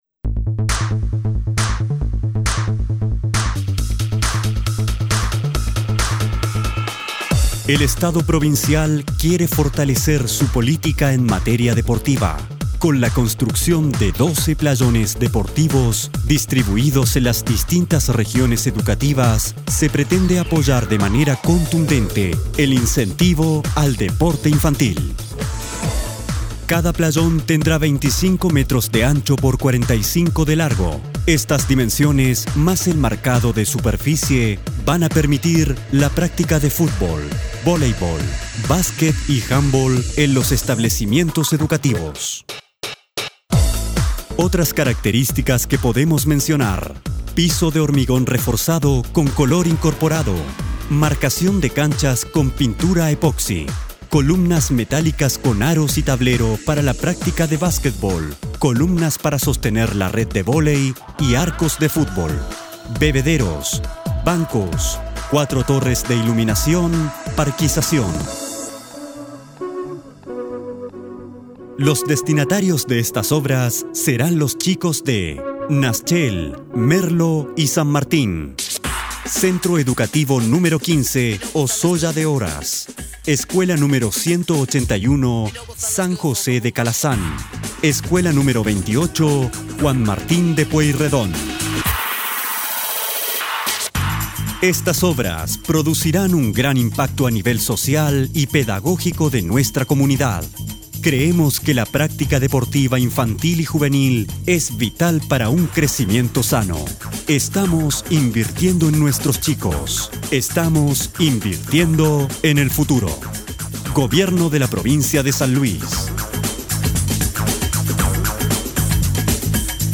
Locutor Voz para Institucionales, comerciales, radio, tv, cine.
Sprechprobe: Industrie (Muttersprache):